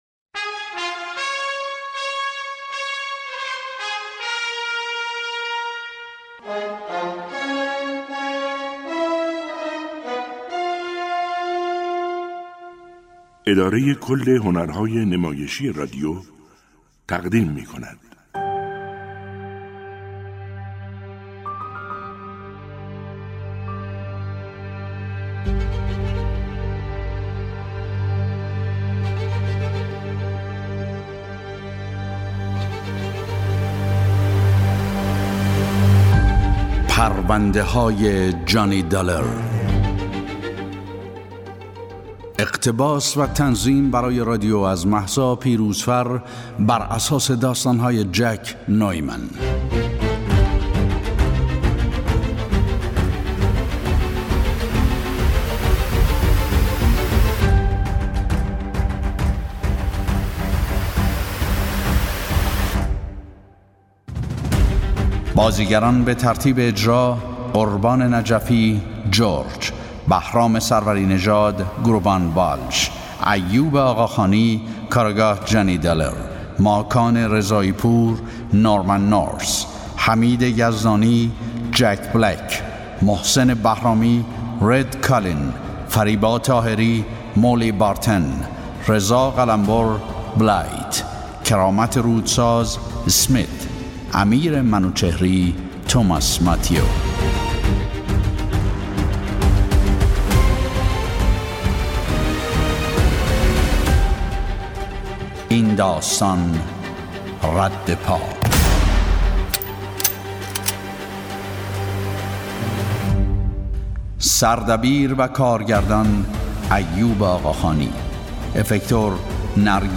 نمایش رادیویی